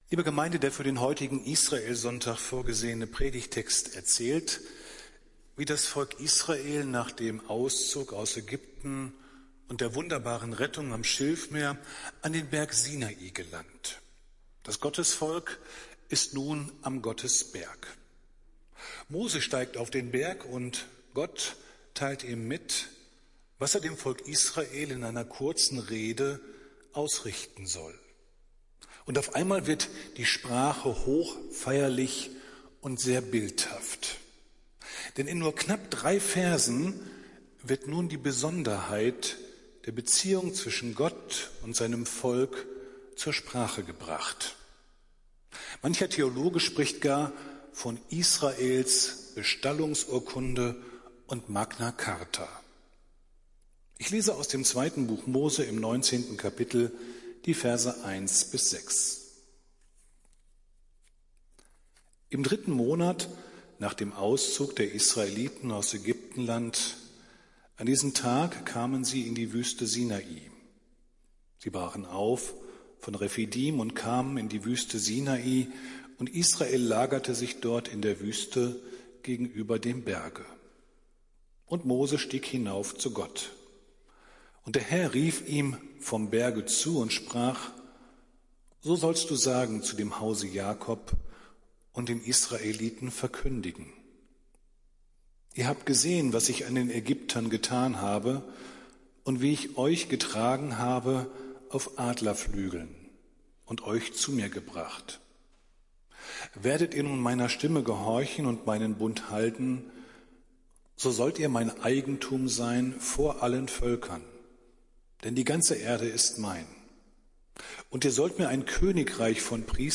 Predigt des Gottesdienstes aus der Zionskirche vom Sonntag, den 08.08.2021